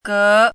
汉字“阁”的拼音是：gé。
“阁”读音
阁字注音：ㄍㄜˊ
国际音标：kɤ˧˥
gé.mp3